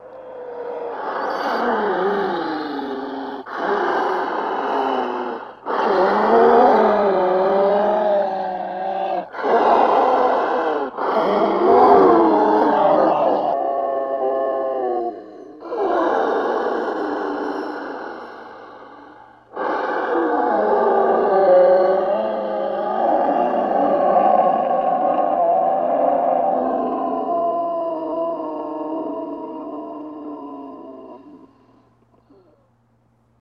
Monster Breathing and Wheezing Mono